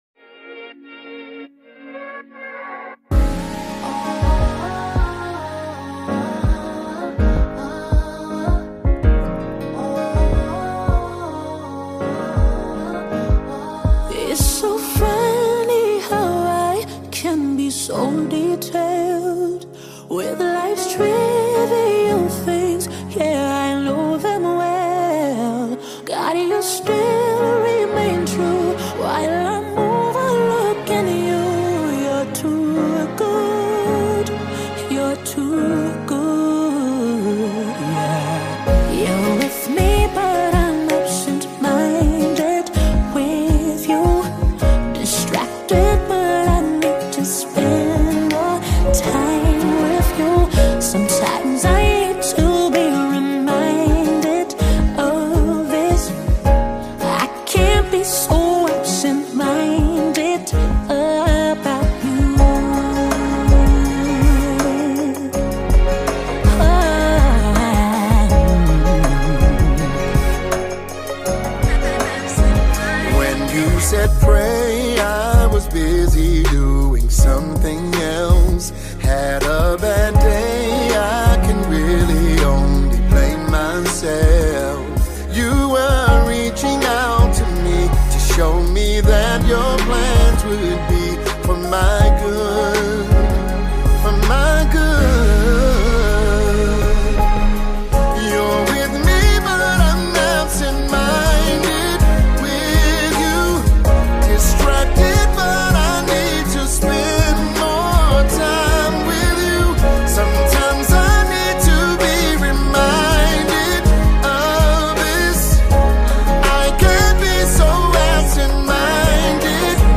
Mp3 Gospel Songs
gospel, contemporary worship, and soul